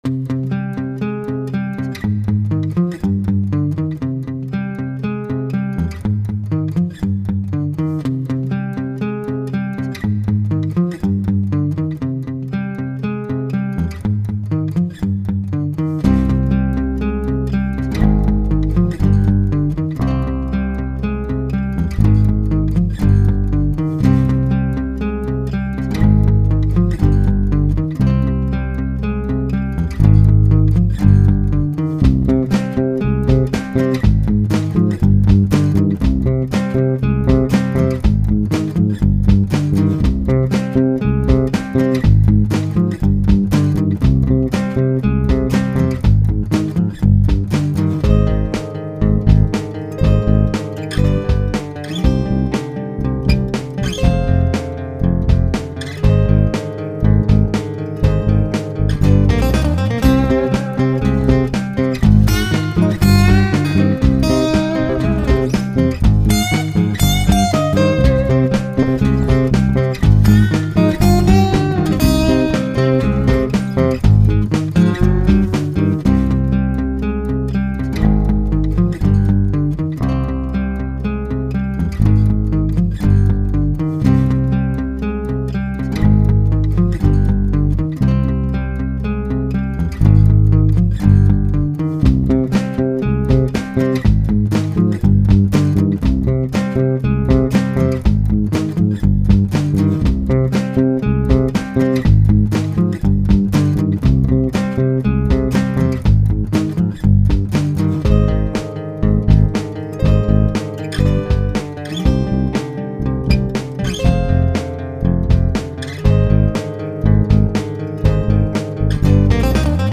sans chant